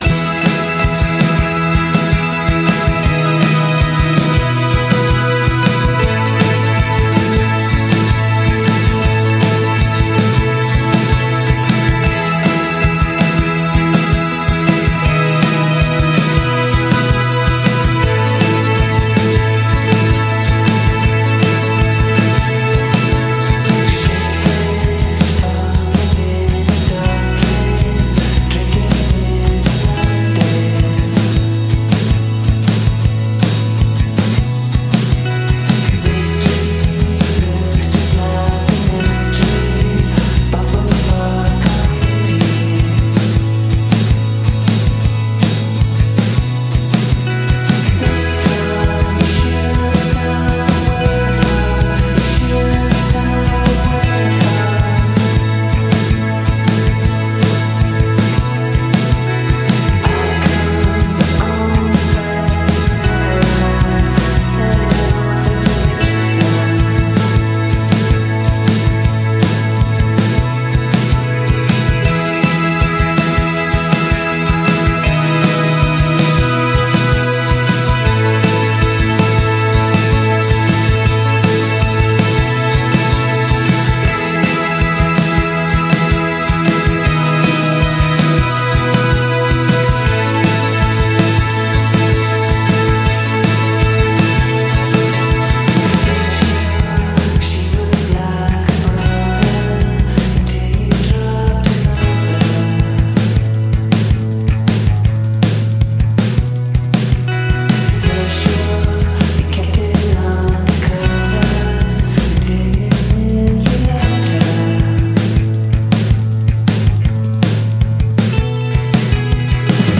tra synth un po' retro e riverberi malinconici.